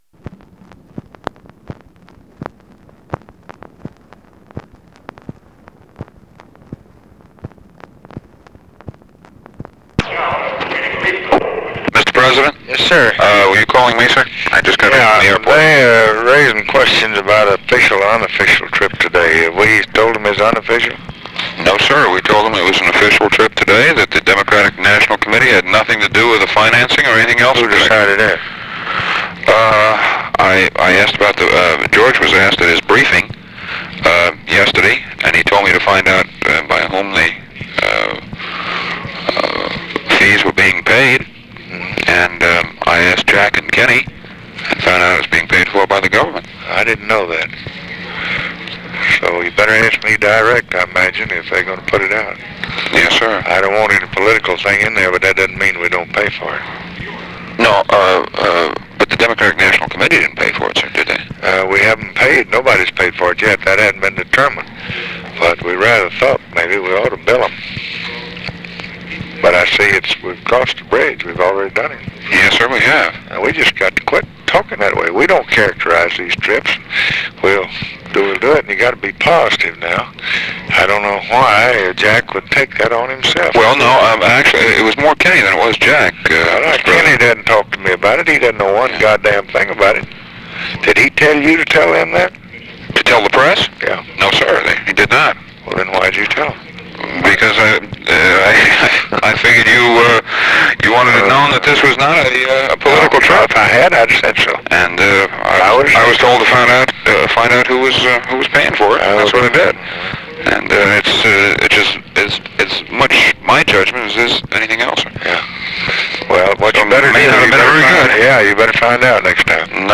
Conversation with MAC KILDUFF, September 15, 1964
Secret White House Tapes